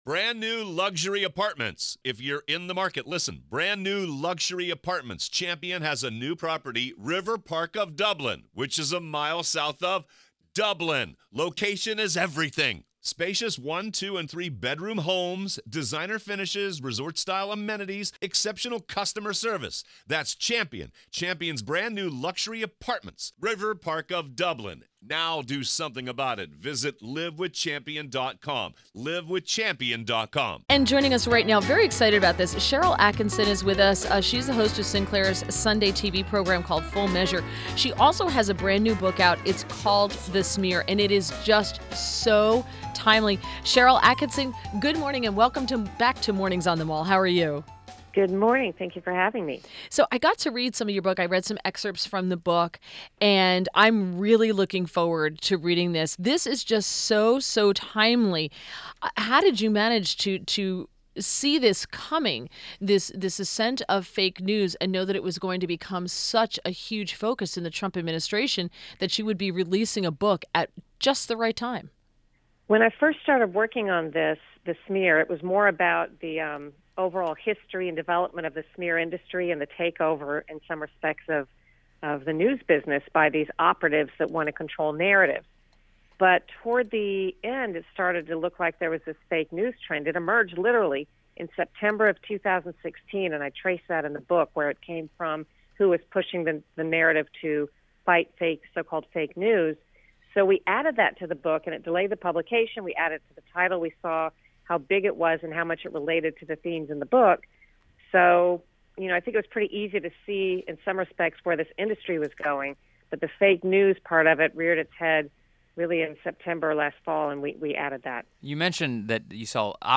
WMAL Interview - SHARYL ATTKISSON 07.03.17